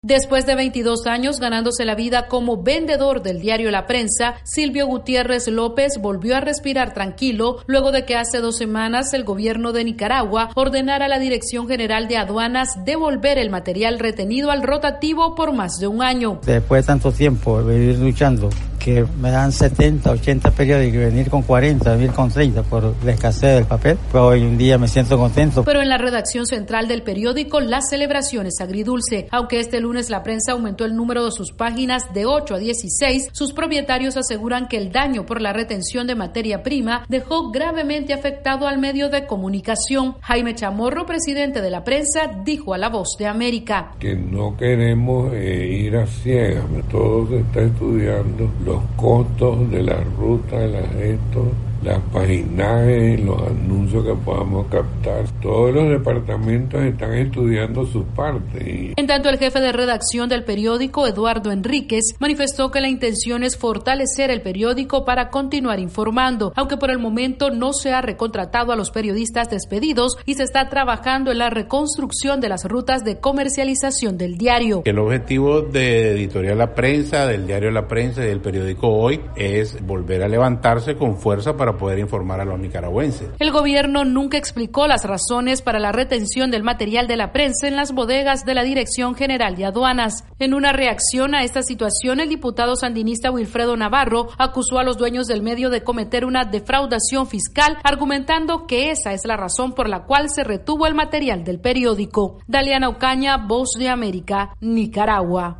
VOA: Informe de Nicaragua